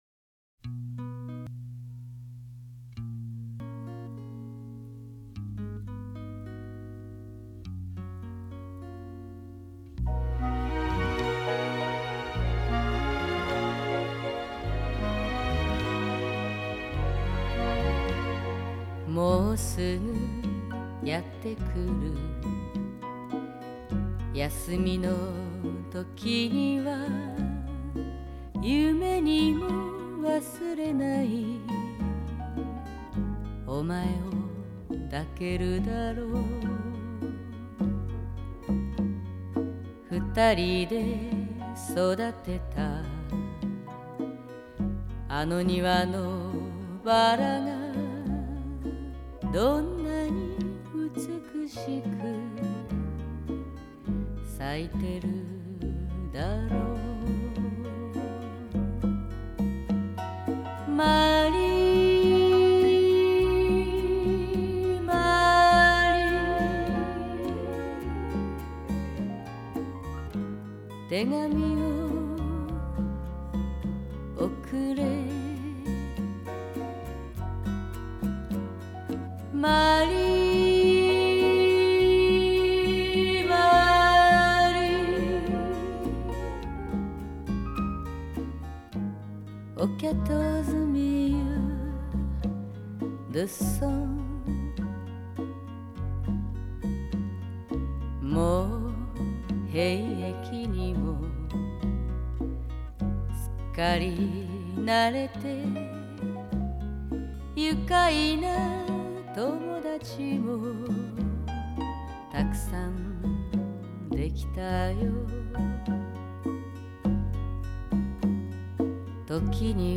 Genre: Japanese Pop